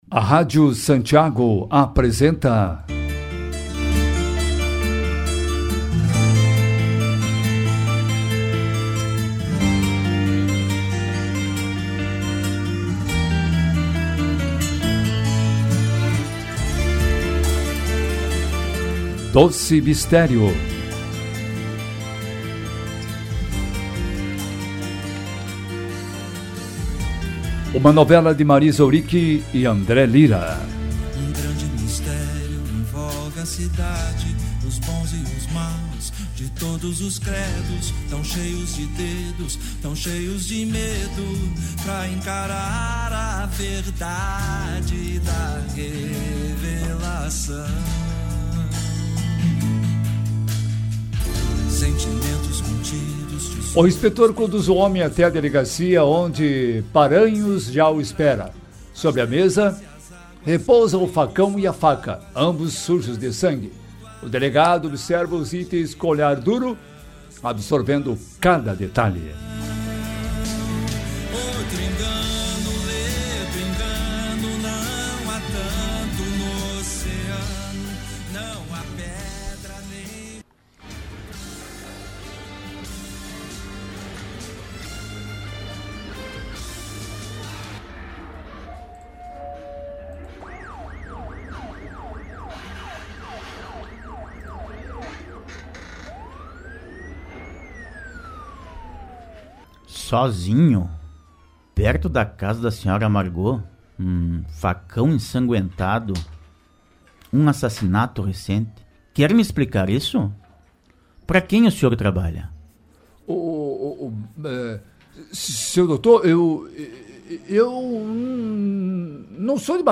Rádio Novela: ouça o segundo capítulo de Doce Mistério